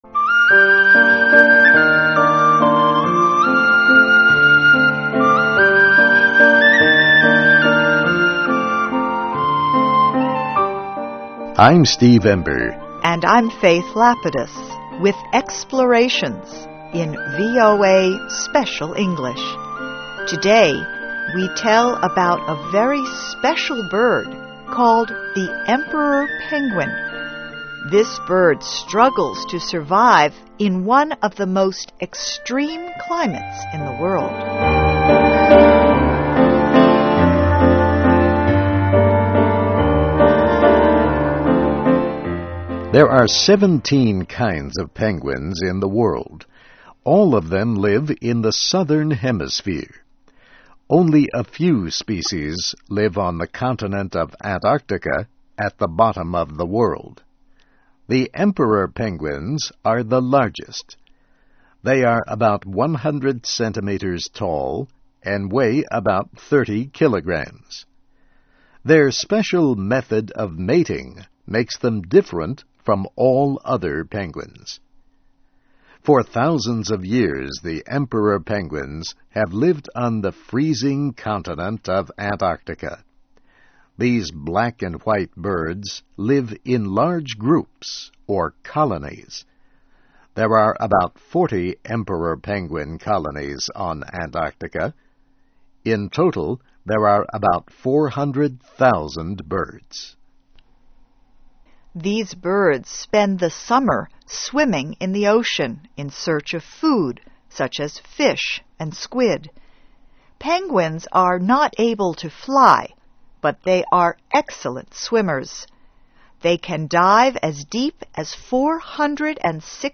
Animals: Emperor Penguins Survive in World's Most Extreme Climate (VOA Special English 2005-08-30)